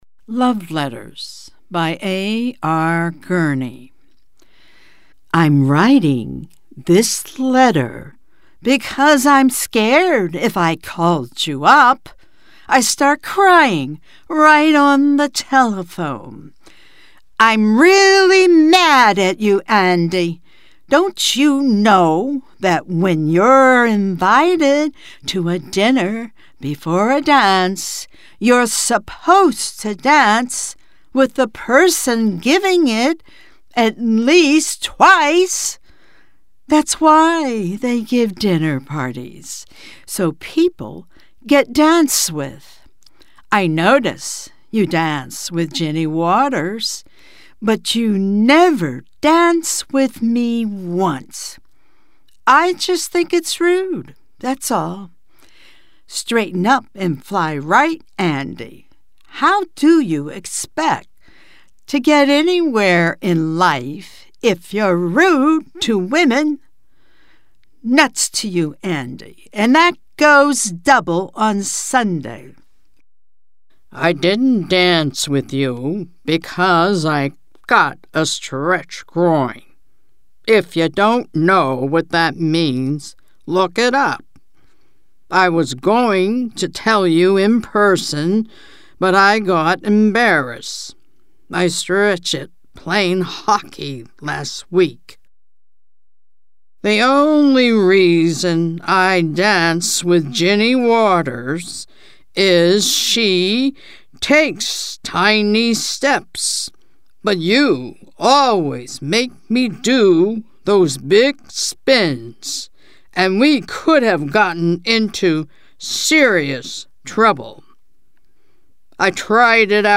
Narrations are recorded with a home studio-quality MSB Mic, providing consistent and reliable performance.